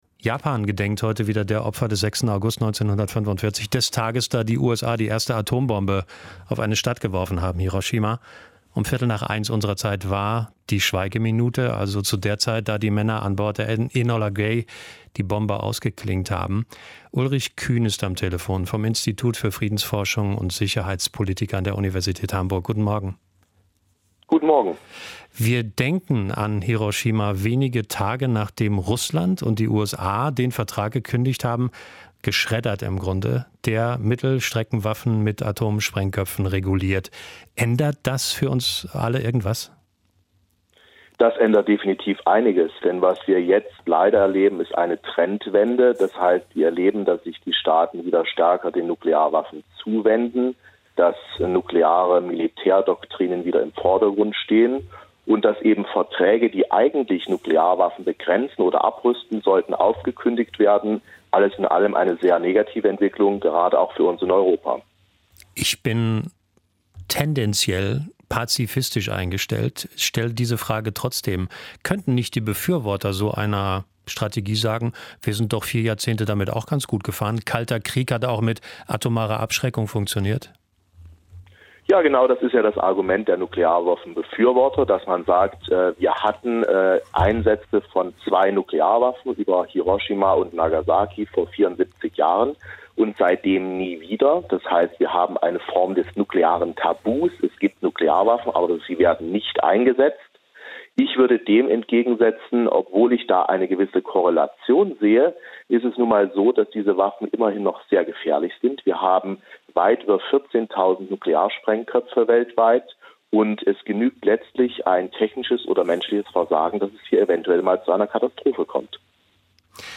Jahrestag des Atombombenabwurfs auf Hiroshima - Interviews im SWR und WDR